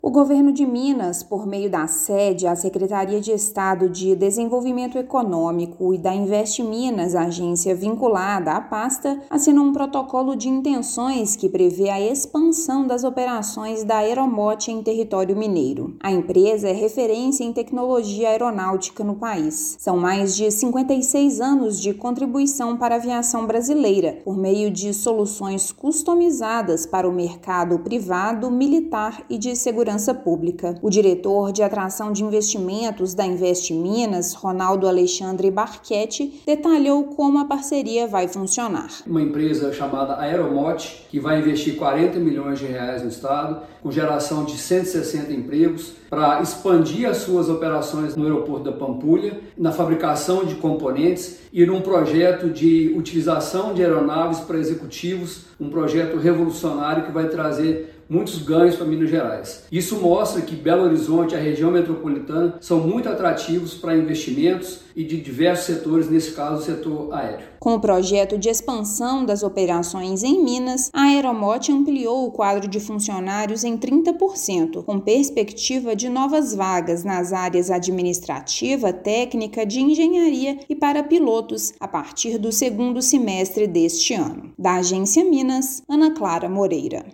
Aeromot já atua no Aeroporto da Pampulha, em Belo Horizonte, mas vai ampliar e expandir negócios na capital mineira. Ouça matéria de rádio.